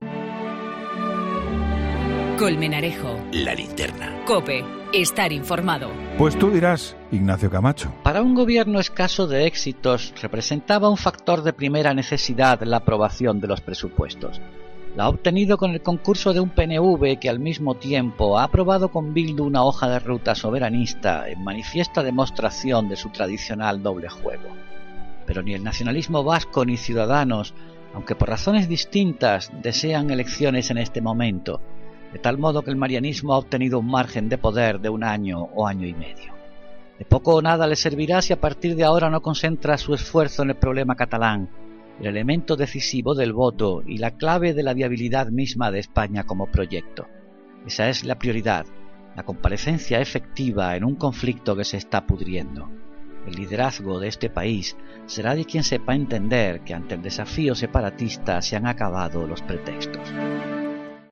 Ignacio Camacho habla en 'La Linterna' de la aprobación de los presupuestos